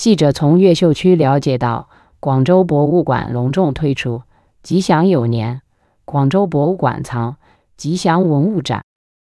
output_xtts_gpu.wav